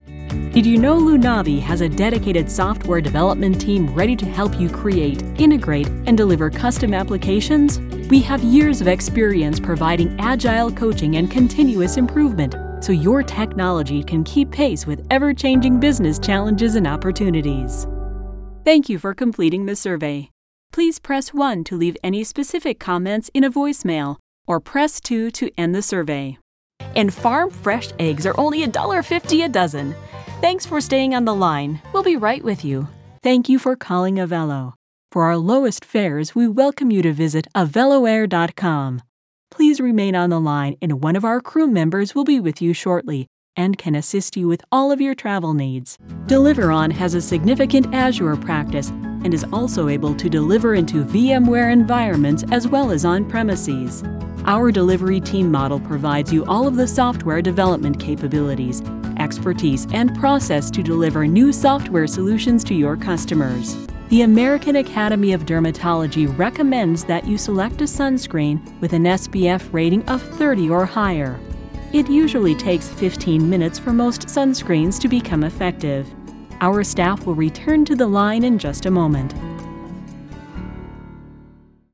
Professional, friendly yet no-nonsence, educated, natural female voice for narrations, eLearning, message-on-hold, commercials, award shows and website videos
Message on Hold
English - Midwestern U.S. English